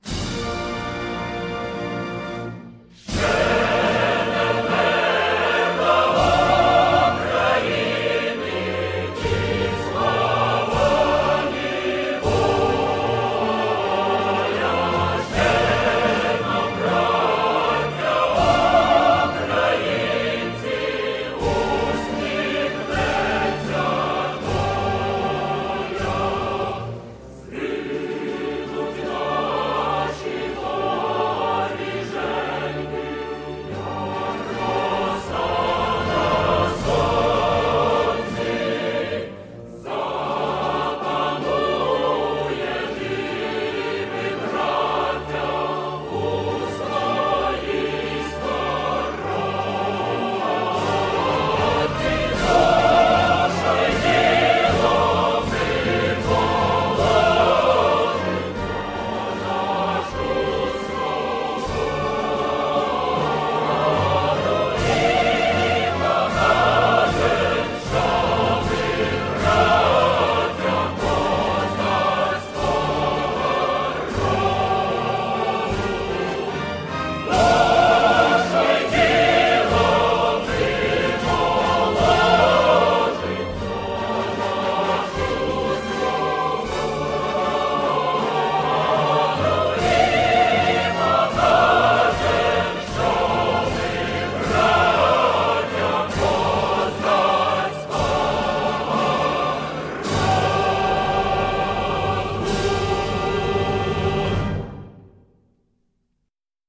Anthem-of-Ukraine_Chorus_Veryovka.mp3